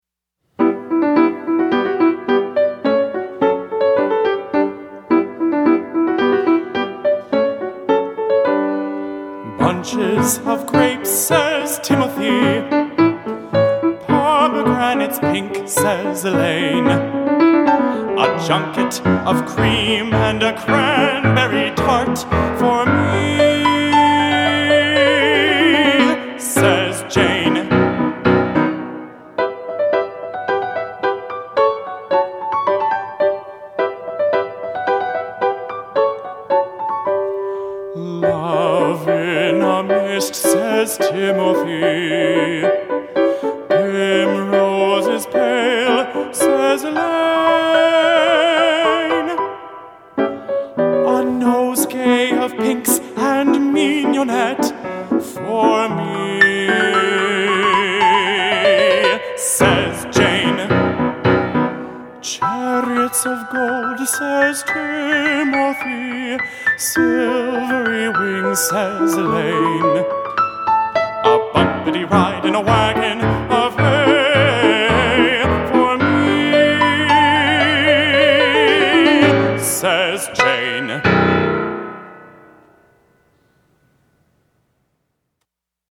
baritone and piano